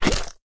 sounds / mob / slime / attack1.ogg